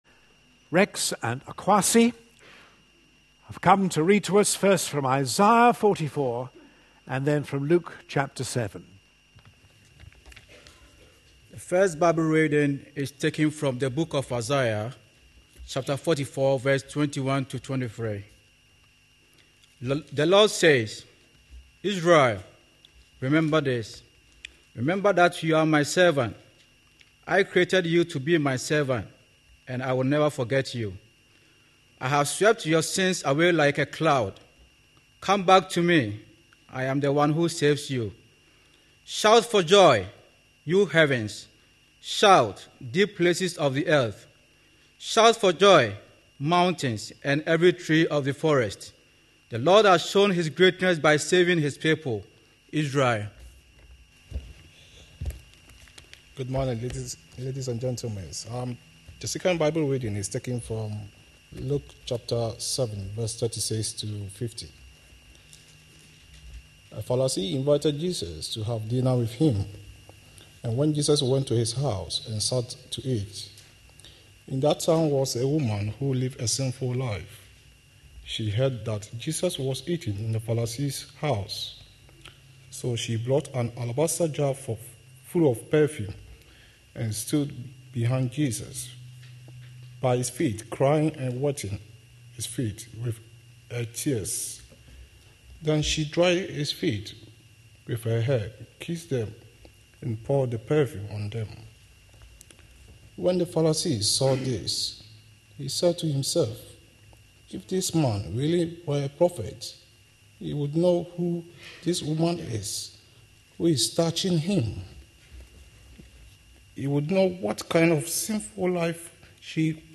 A sermon preached on 5th February, 2012, as part of our Looking For Love (10am Series) series.